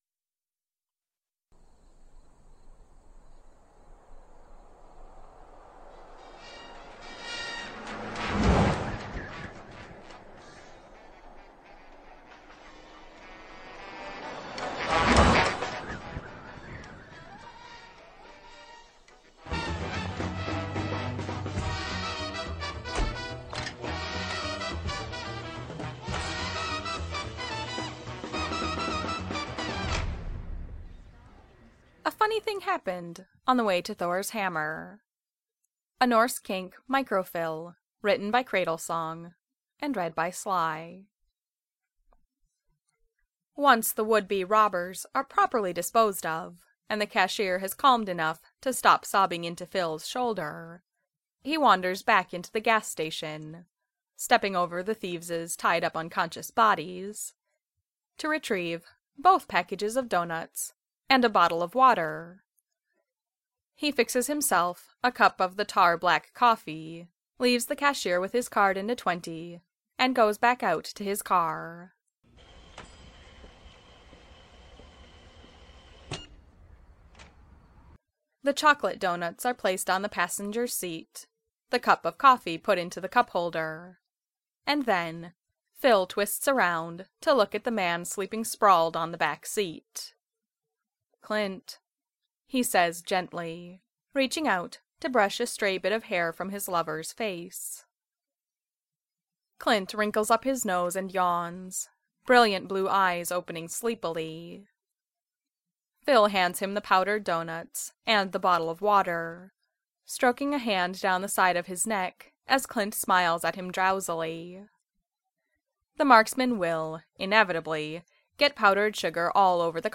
I recorded this wonderful little ficlet using some of the audio from the Marvel short "A Funny Thing Happened On the Way to Thor's Hammer."
I really like how well you integrated the narrative and sound from the film.